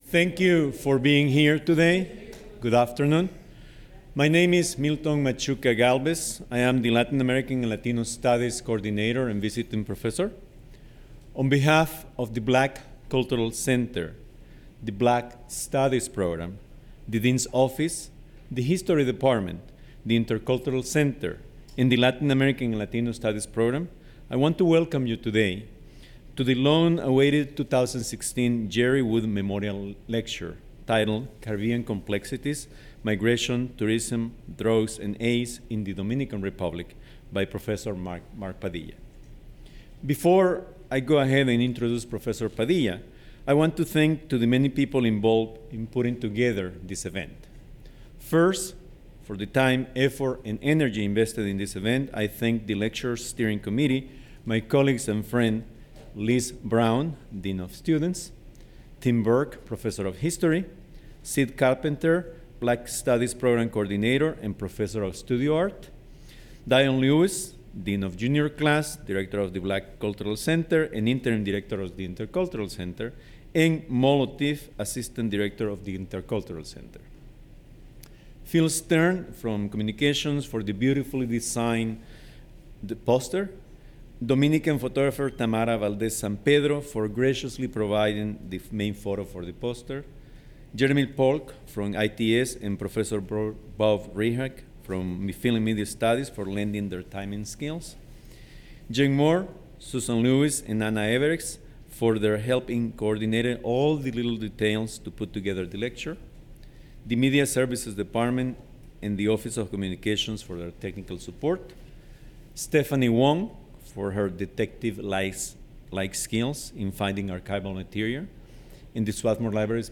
Memorial Lecture